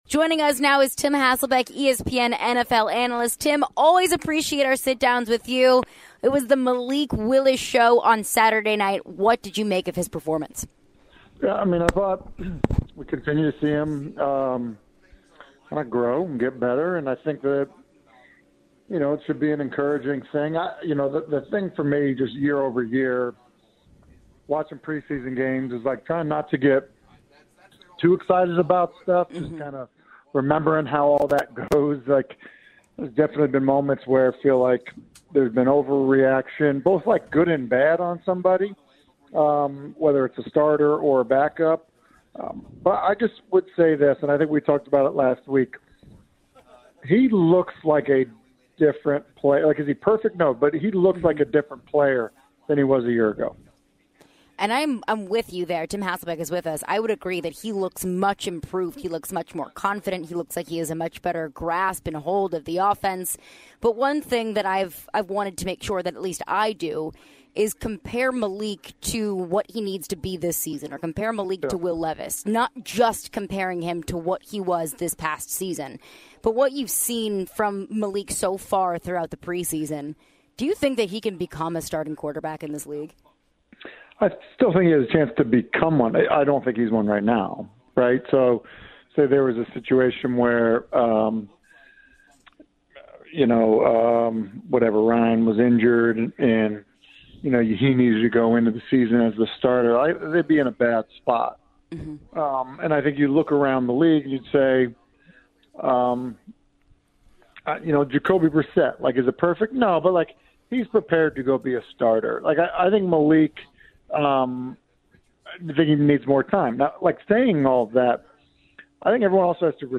Tim Hasselbeck Interview (08-22-23)
ESPN's Tim Hasselbeck joins the show and talks about the Titans issues and the QB's situations. Later, Tim also mentions Jonathan Taylor.